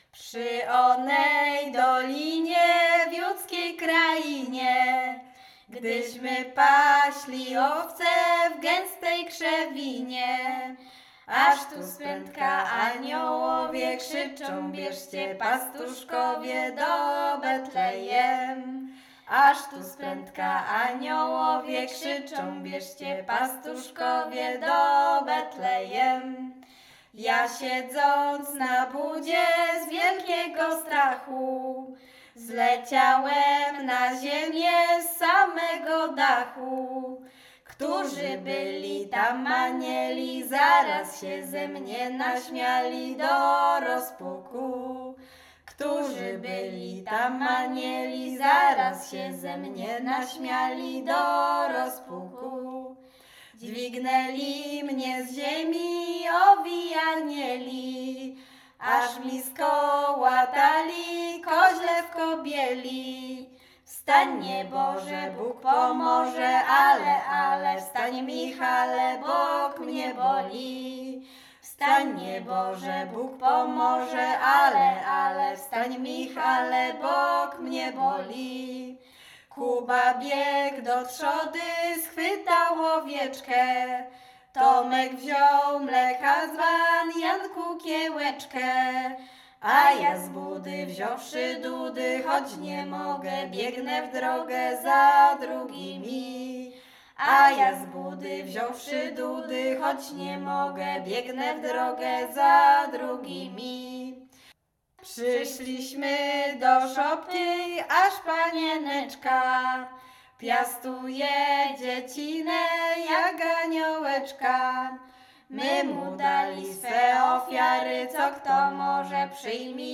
Pastorałka
zima pastorałki kolędowanie kolędy gody